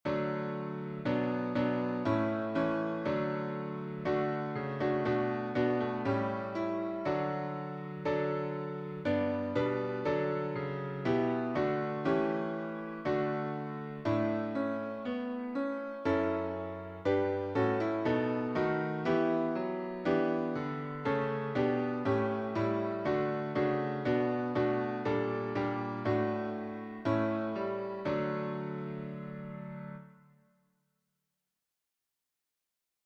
SATB
Accompaniment